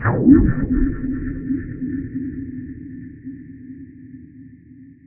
Descarga de Sonidos mp3 Gratis: zumbido electronico.
descargar sonido mp3 zumbido electronico